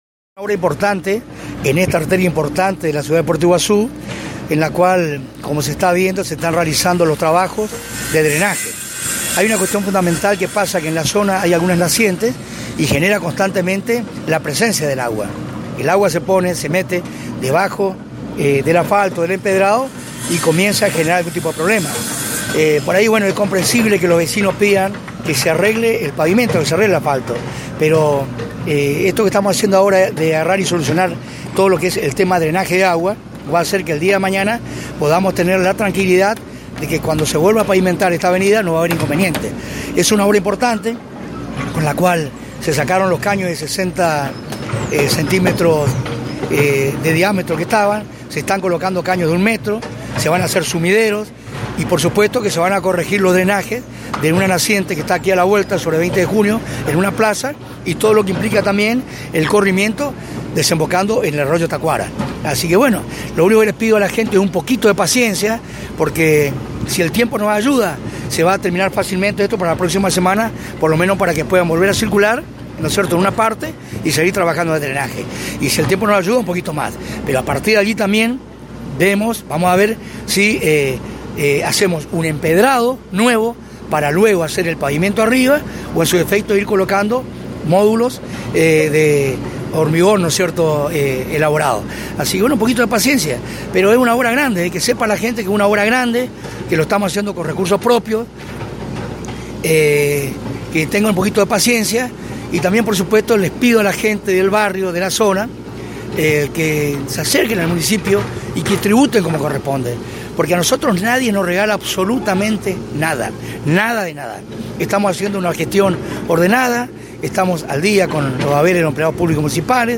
Claudio Filippa (Intendente de Puerto Iguazú)